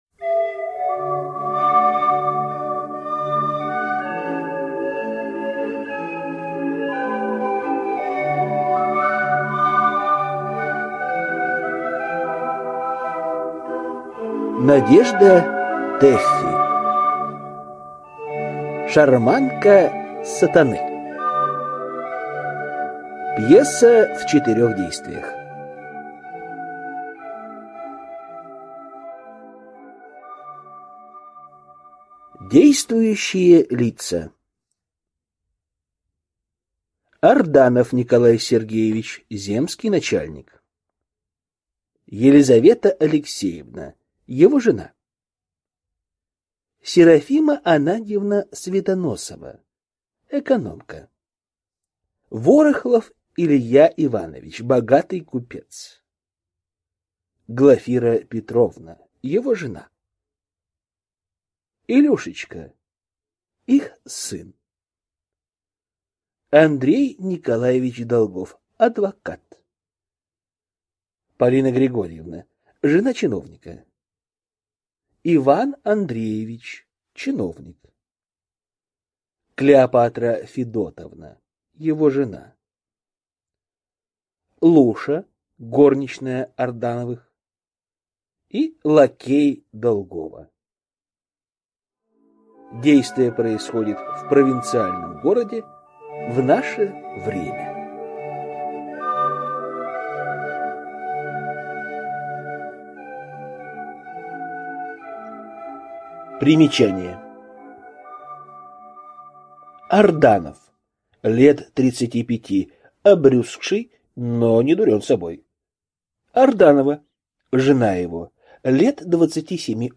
Аудиокнига Шарманка Сатаны | Библиотека аудиокниг